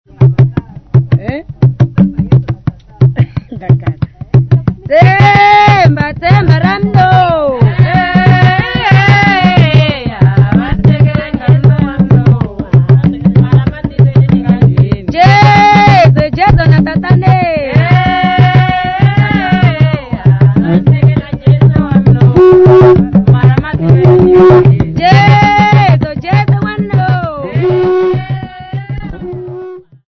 Group of men, women and children
Folk music
Field recordings
sound recording-musical
Indigeneous music.